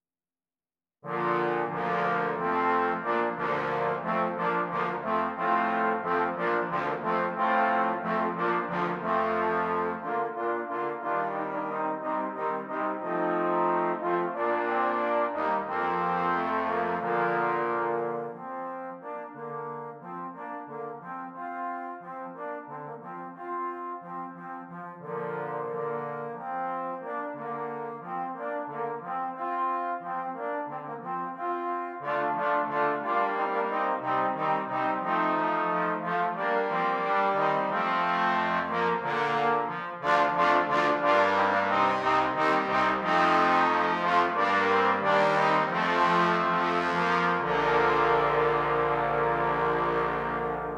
5 Trombones